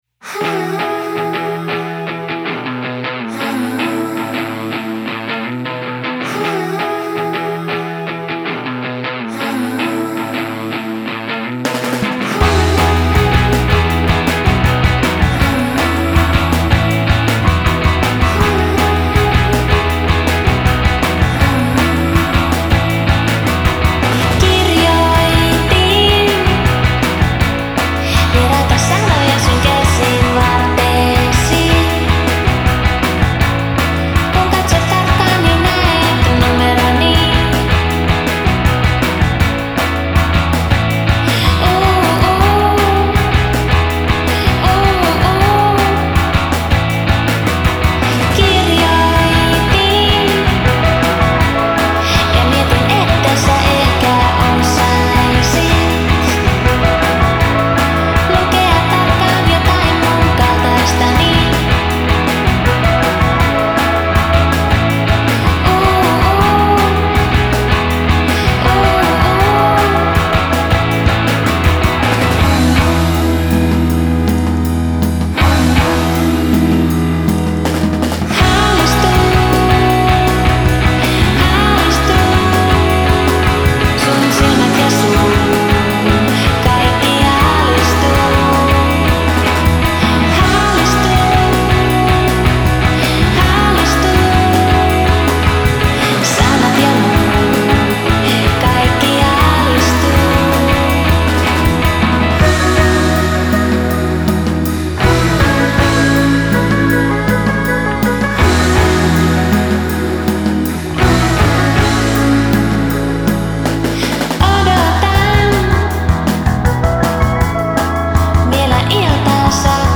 Style: Dream Pop